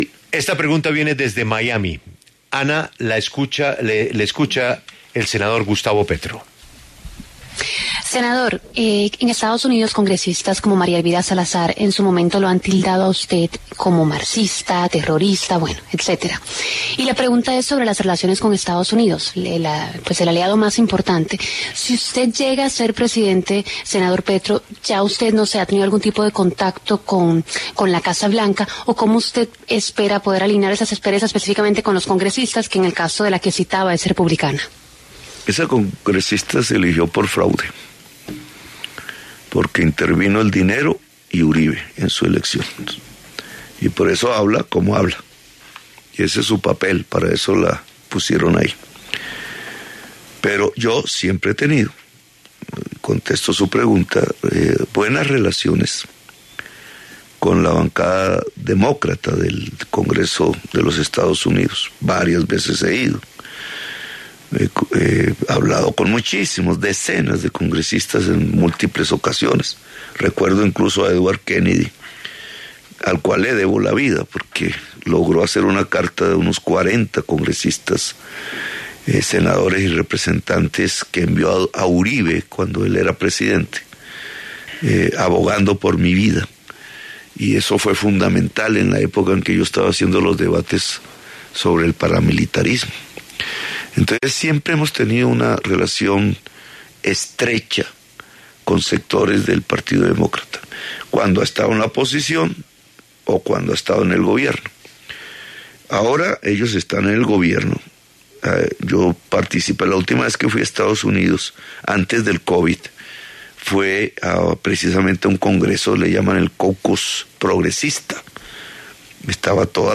El precandidato presidencial Gustavo Petro habló en La W a propósito de los temas a los que daría prioridad en la agenda internacional en caso de ganar las elecciones.
Gustavo Petro, precandidato del Pacto Histórico, habla en La W